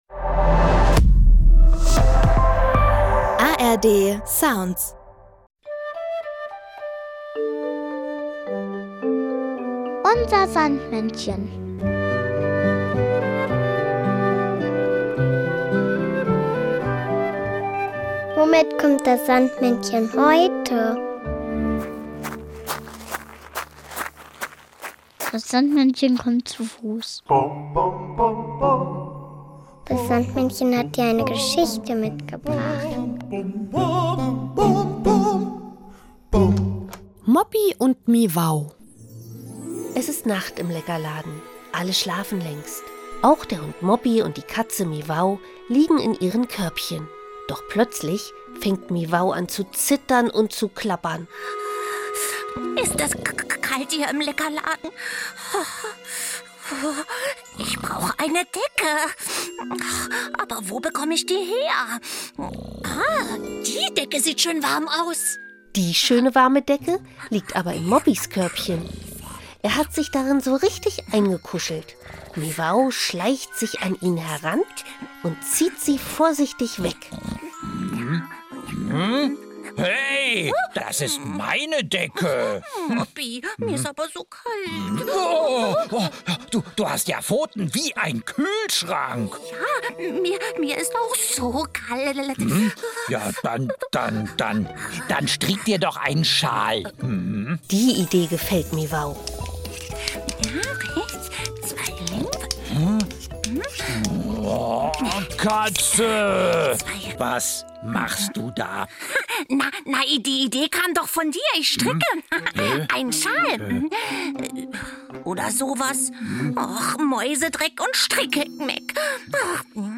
sondern auch noch das Kinderlied "Manchmal" vom Gorilla Club.